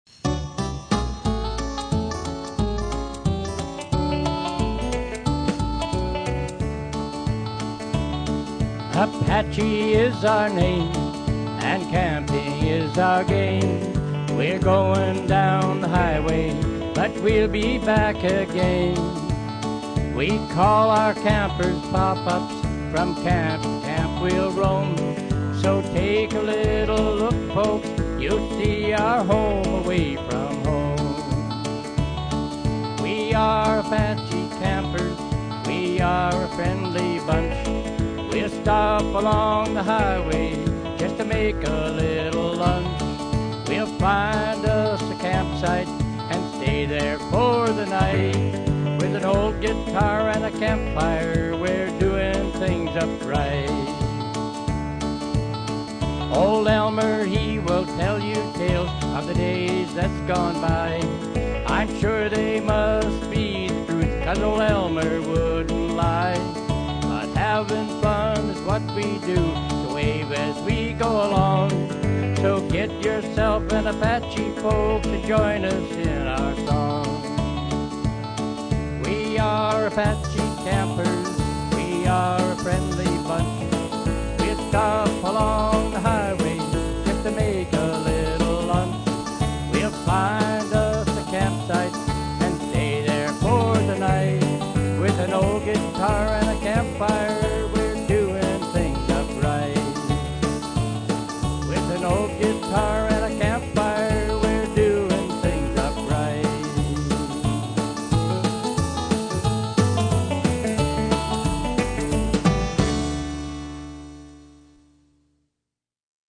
original song
Canadian singer